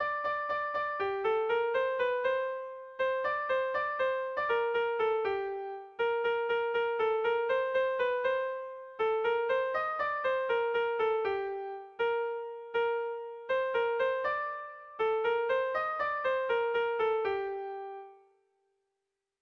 Gabonetakoa
ABDEFE